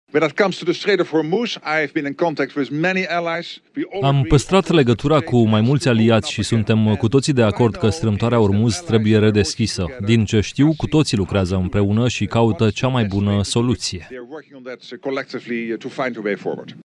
Șeful NATO a făcut declarațiile într-o conferință de presă în Norvegia.
18mar-18-Rutte-discutam-cu-aliatii-NATO-Tradus.mp3